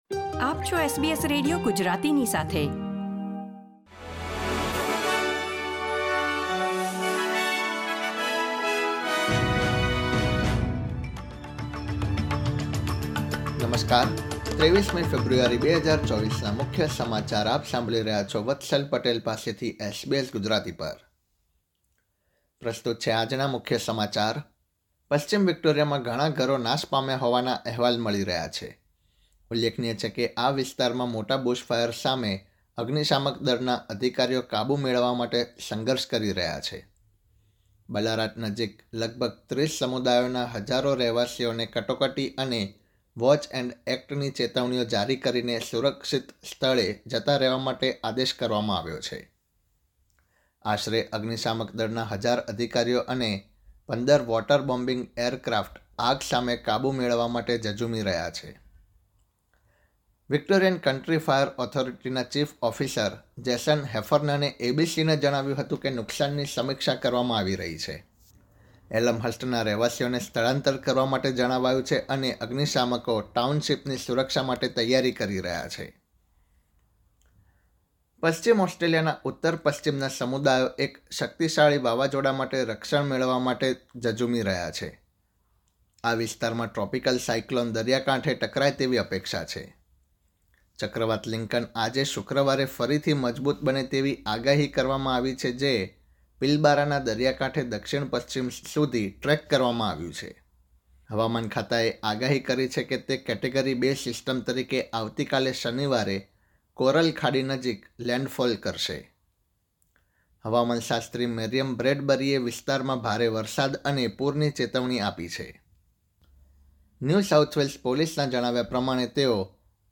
SBS Gujarati News Bulletin 23 February 2024